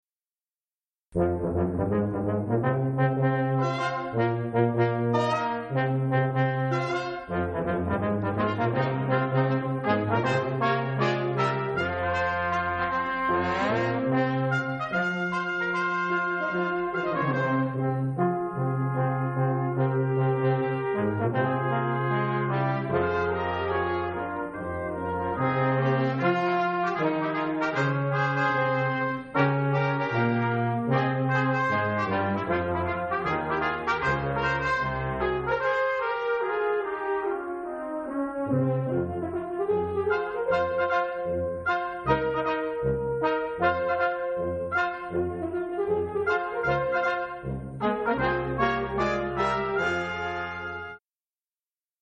Seton Performance Series - 1/18/2004
Three Spirituals.MP3